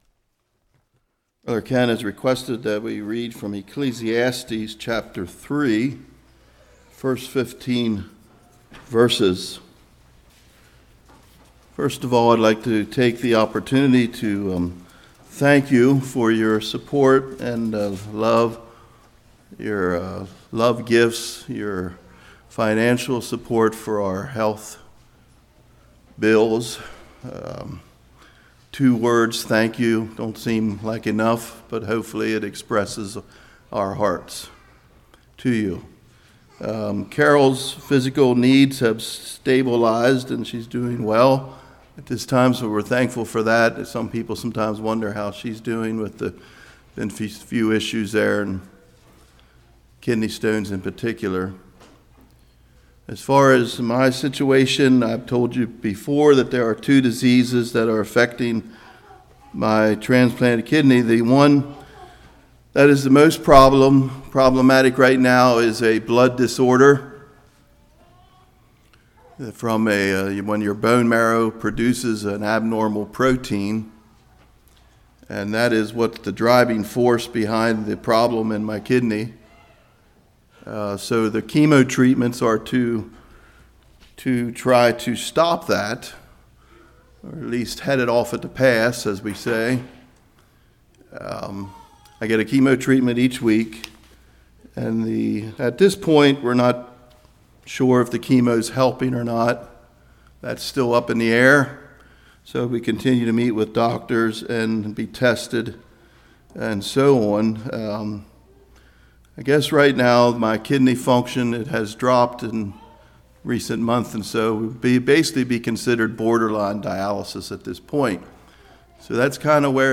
Ecclesiastes 3:1-15 Service Type: Morning Our time on earth is owned by God.